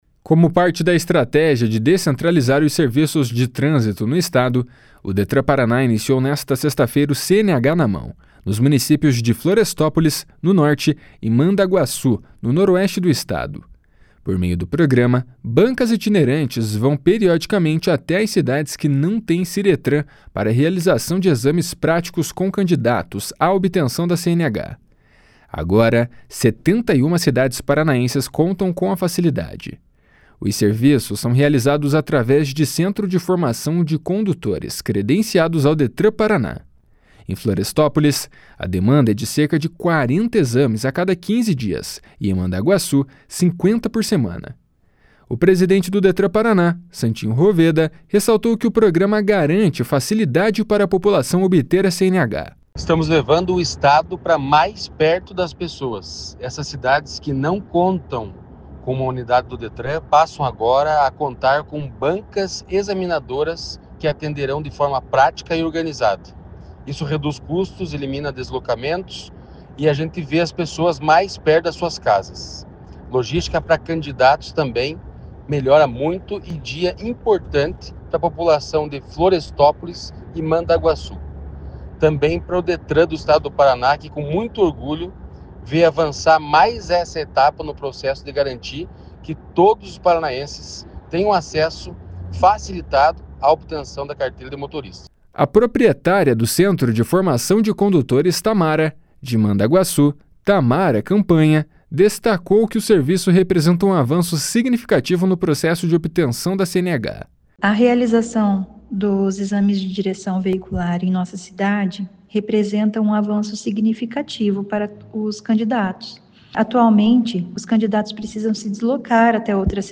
O presidente do Detran-PR, Santin Roveda, ressaltou que o programa garante facilidade para a população obter a CNH.